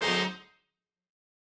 GS_HornStab-D7b2b5.wav